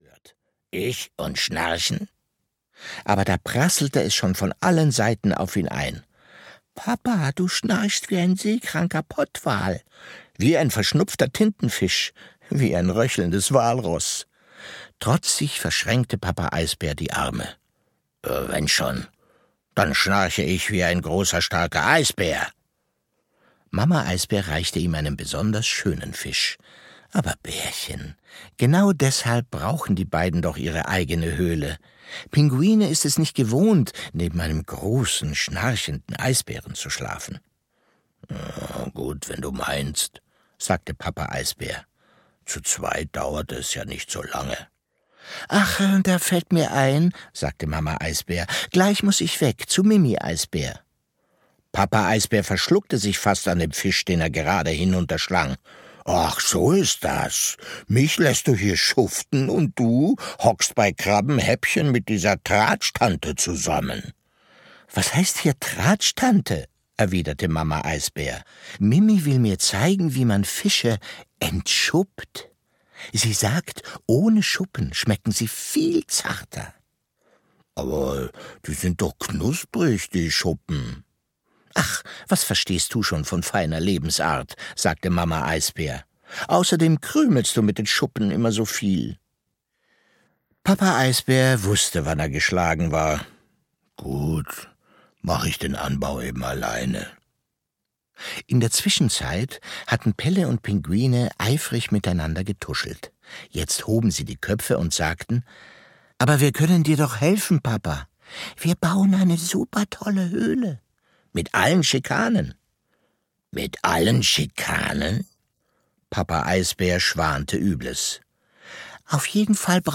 Pelle und Pinguine 2: Auch ein Eisbär geht zur Schule - Henning Callsen - Hörbuch